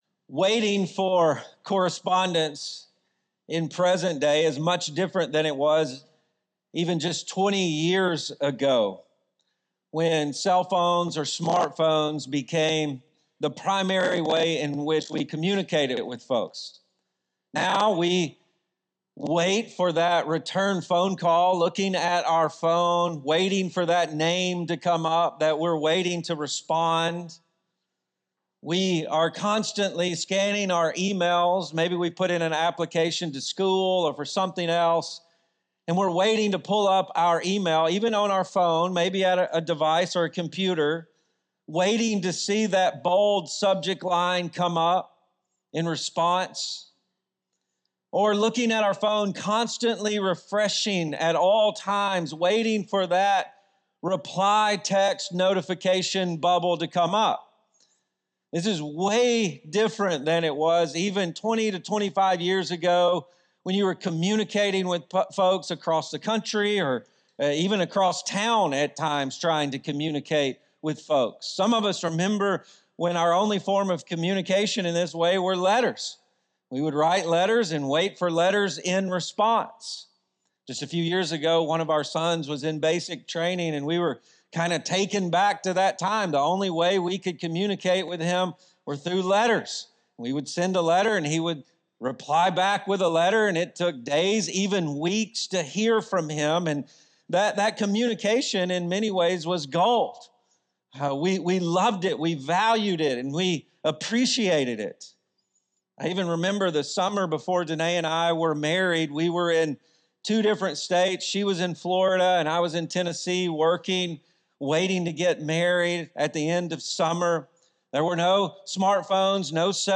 Ashland Church Sermons 1 & 2 Thessalonians (1 Thessalonians 1: 1-3) Apr 27 2025 | 00:44:25 1x 00:00 / 00:44:25 Subscribe Share Spotify RSS Feed Share Link Embed